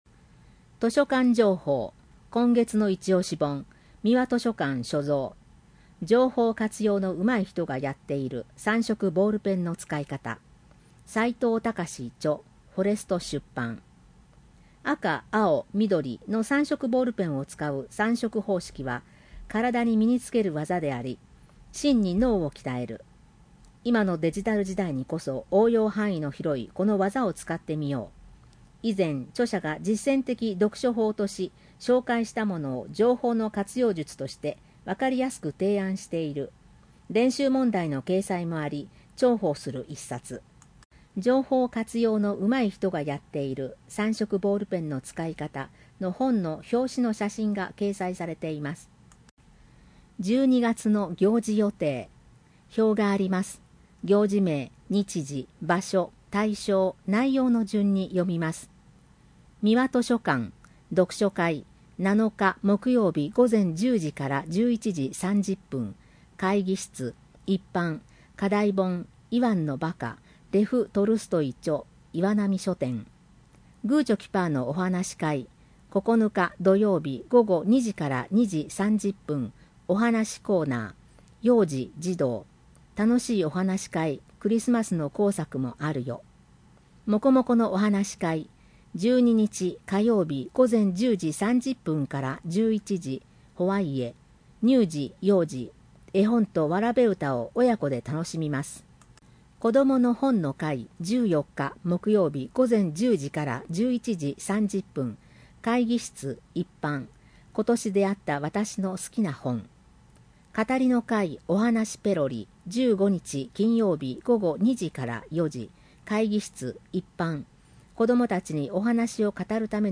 ボランティア団体「音訳あま」の皆さんの協力により、広報あまを音声化して、市公式ウェブサイトに掲載しています。